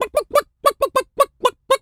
Animal_Impersonations
chicken_cluck_bwak_seq_04.wav